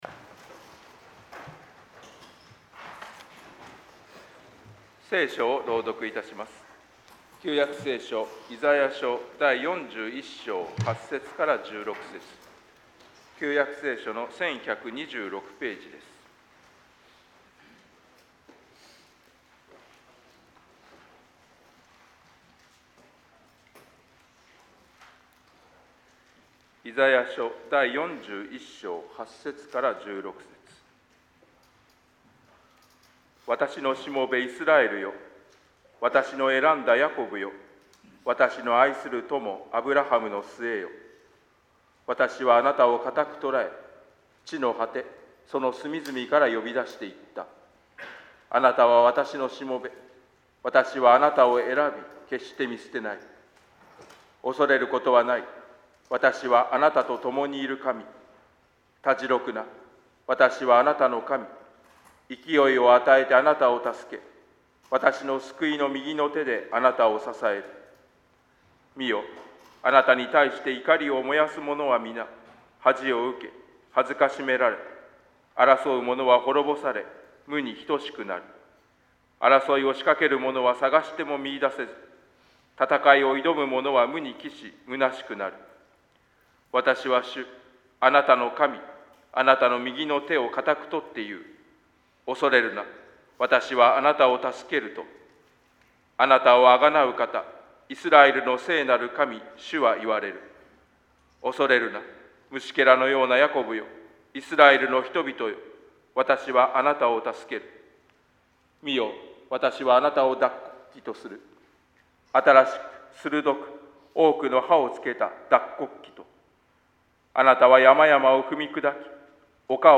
説 教